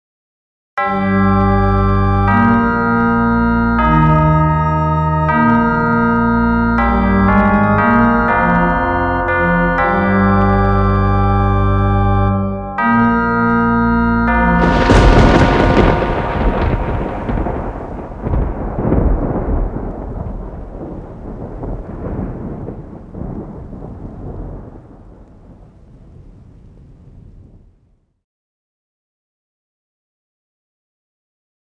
We recorded the notes that appear in this image: MP3 Version MIDI Version